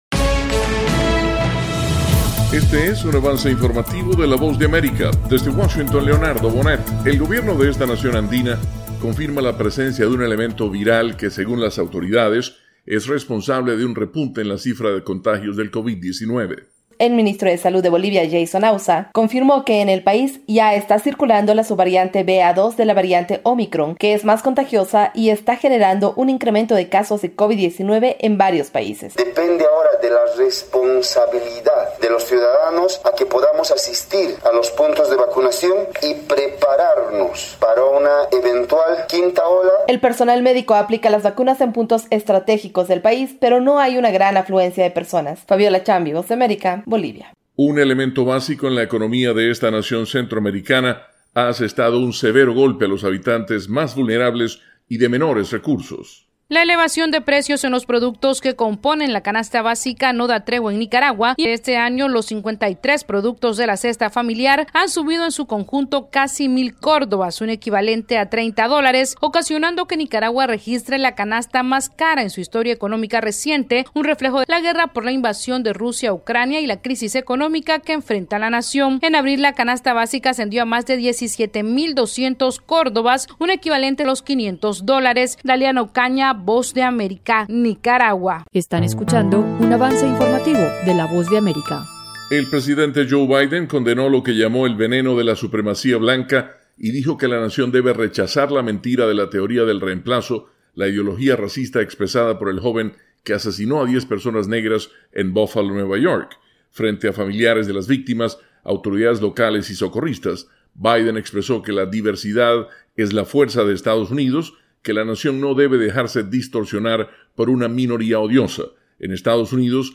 Avance Informativo - 3:00 PM